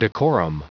Prononciation du mot decorum en anglais (fichier audio)
Prononciation du mot : decorum